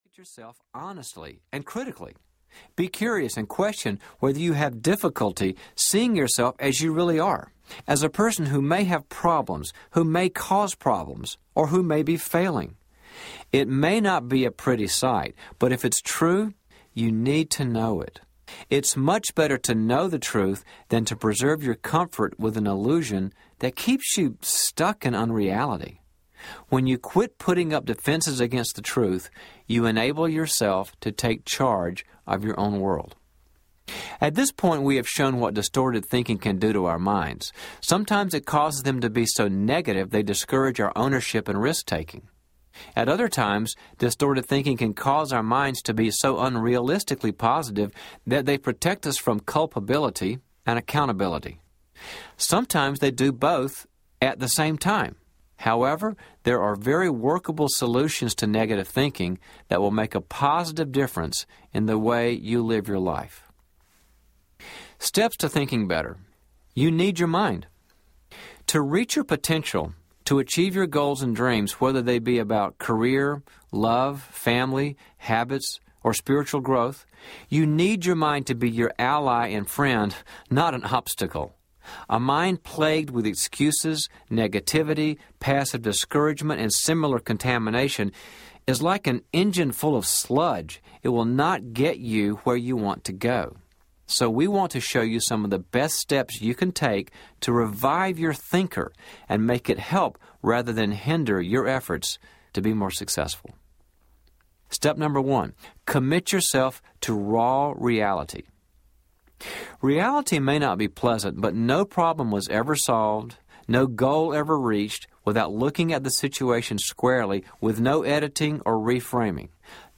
It’s Not My Fault Audiobook
Dr. Henry Cloud & Dr. John Townsend